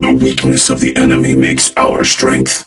robo_bo_lead_vo_02.ogg